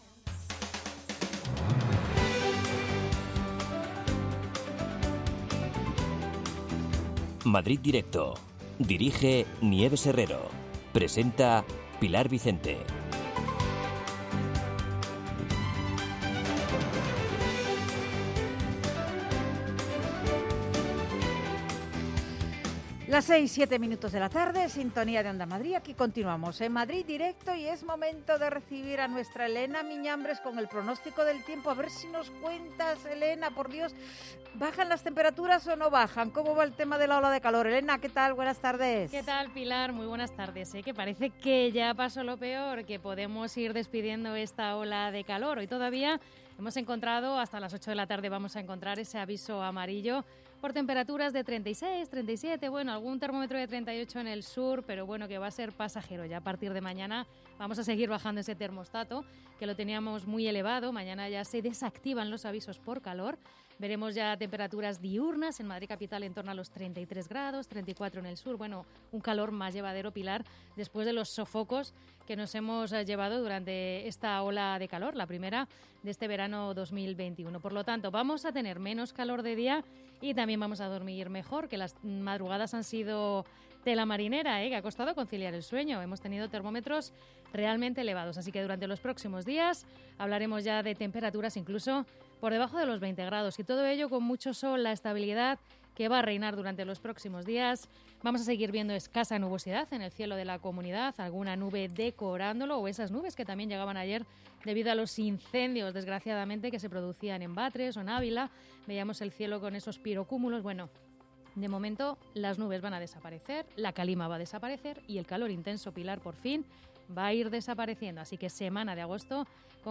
Nieves Herrero se pone al frente de un equipo de periodistas y colaboradores para tomarle el pulso a las tardes.
La primera hora está dedicada al análisis de la actualidad en clave de tertulia.